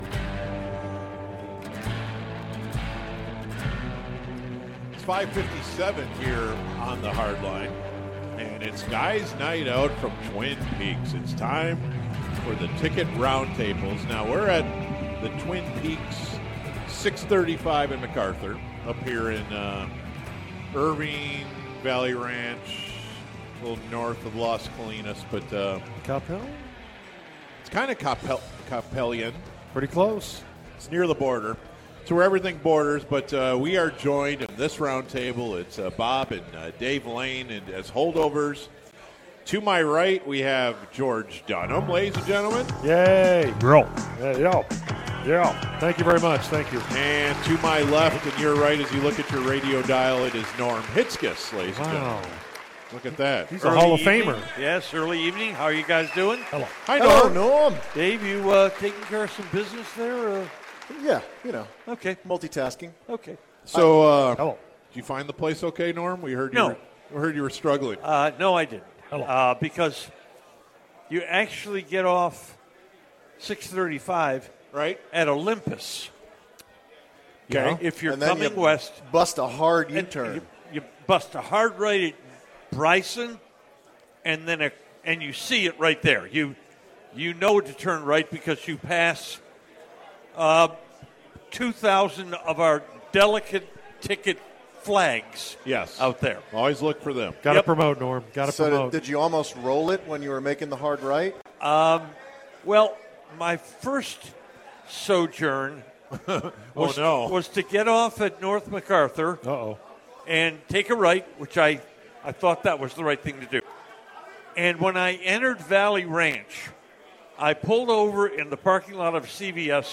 The boys were at Twin Peaks for a Guys Night Out.